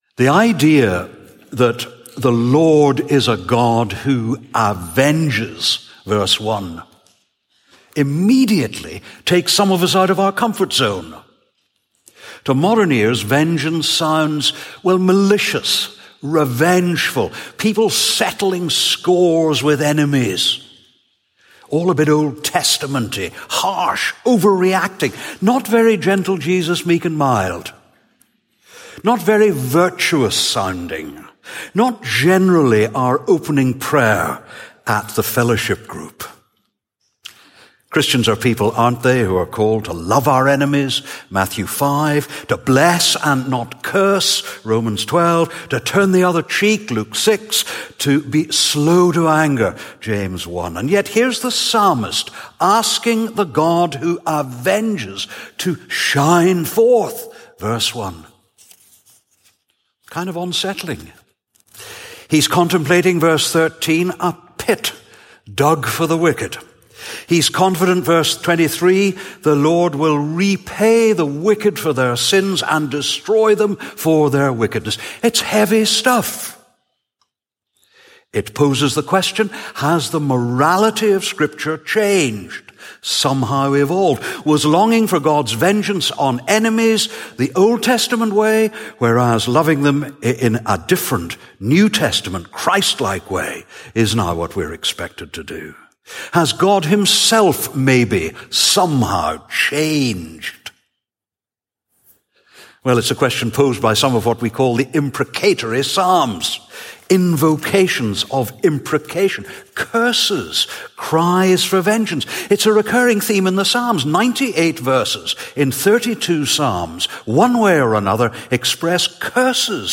All Souls Sunday Sermons Podcast - A Cry for Justice (Psalm 94) | Free Listening on Podbean App